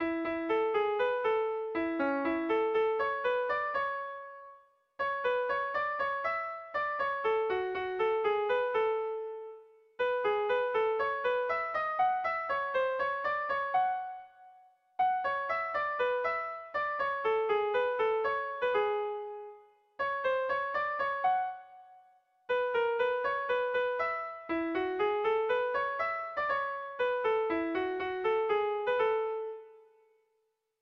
Air de bertsos - Voir fiche   Pour savoir plus sur cette section
Sentimenduzkoa
Hamabiko txikia (hg) / Sei puntuko txikia (ip)